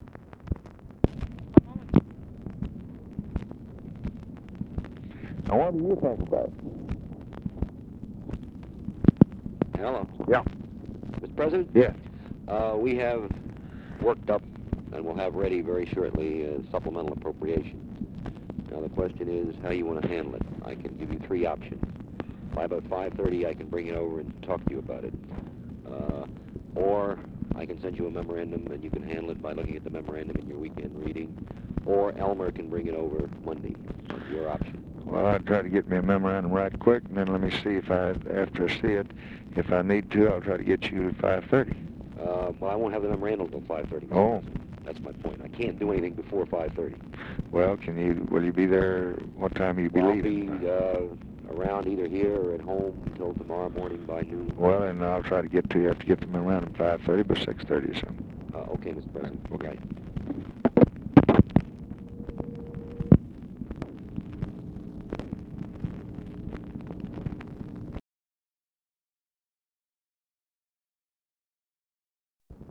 Conversation with CHARLES SCHULTZE and OFFICE CONVERSATION, August 21, 1965
Secret White House Tapes